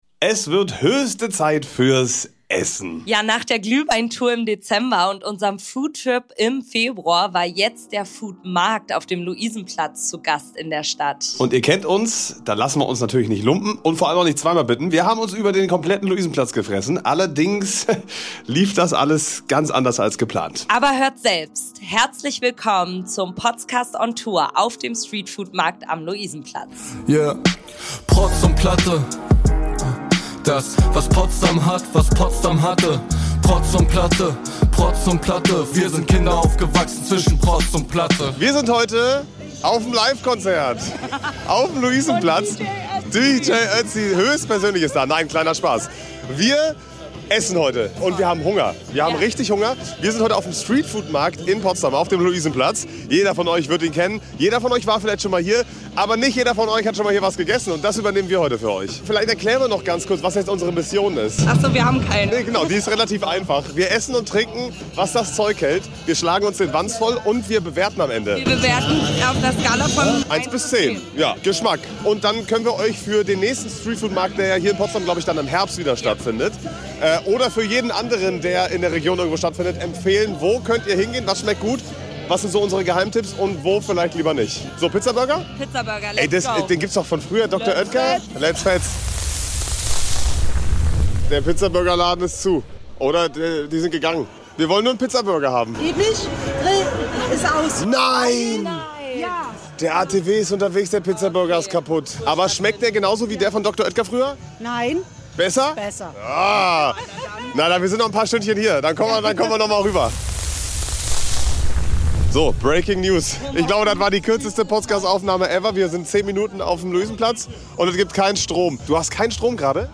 Und ihr kennt uns, da lassen wir uns nicht zwei Mal bitten: Wir haben uns über den Luisenplatz gefressen! Allerdings lief das alles ganz anders als geplant! Viel Spaß mit unserer neuen “Potscast On Tour”-Folge auf dem Street Food Markt am Luisenplatz!